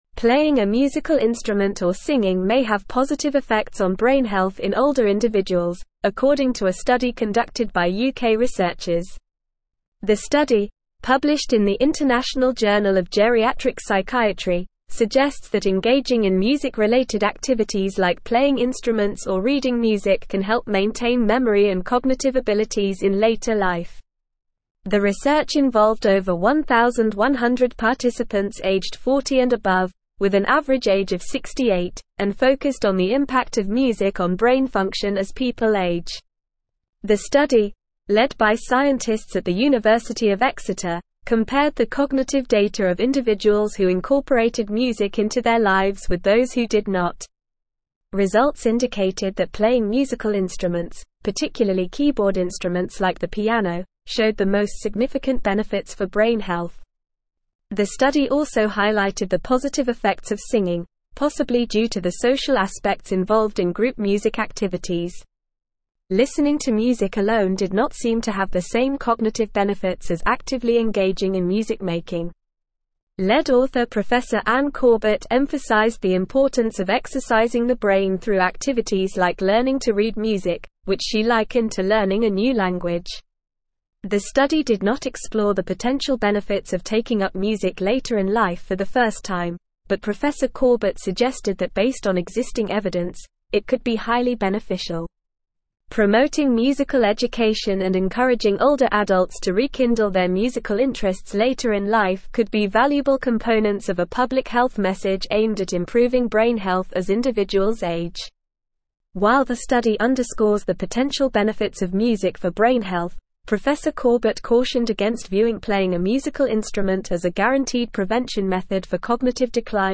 Normal
English-Newsroom-Advanced-NORMAL-Reading-Music-and-Brain-Health-Benefits-of-Playing-Instruments.mp3